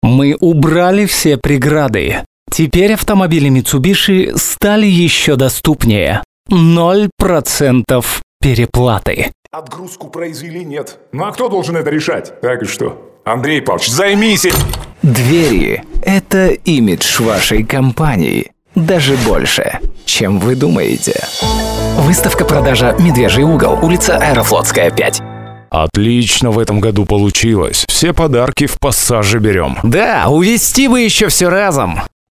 микрофон Rode NT 2 , преамп DIGILAB Ginger SPM-100,ART MDC2001 Stereo master Dynamics controller, M-AUDIO Delta Audiophile 192